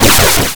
bfxr_splash.wav